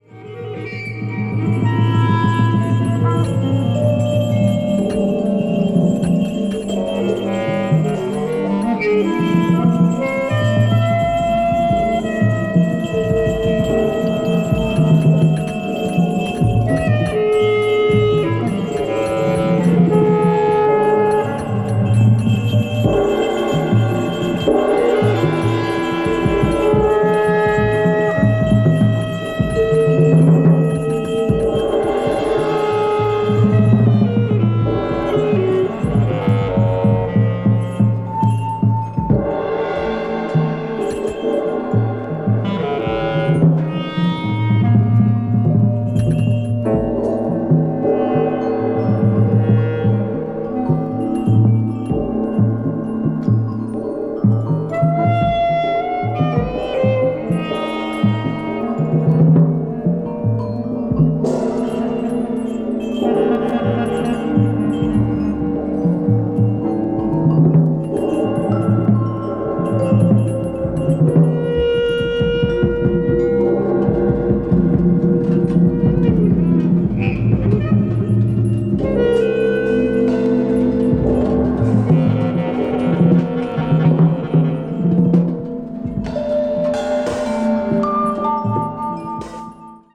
avant-jazz   ethnic jazz   free improvisation   free jazz